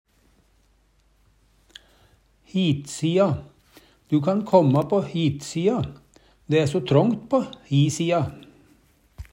hitsia - Numedalsmål (en-US)